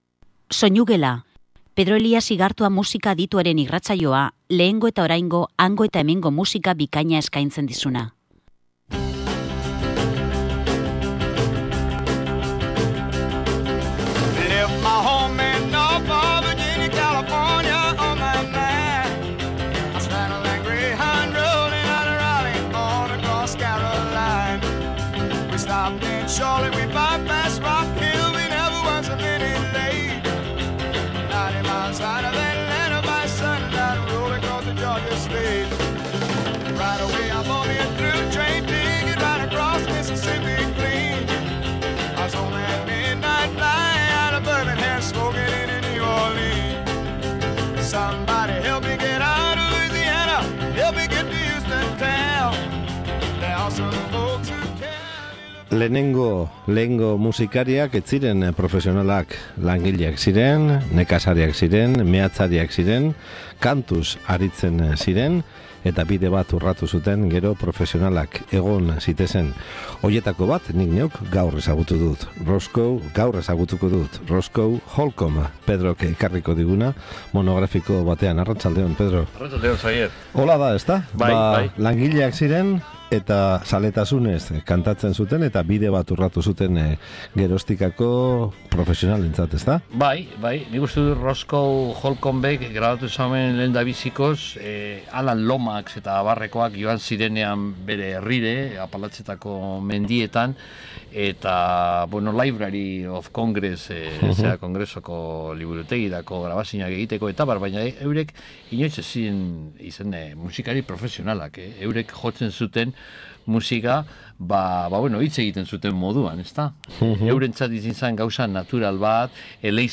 Banjoaz lagunduta, ahoan musugitarra hartuta edo a capella kantatuz, aspaldian inguru hartan nagusi ziren eta gaur egun folkloretzat hartzen diren bluegrass doinuak ekarri dizkigu Roscoe Holcombek.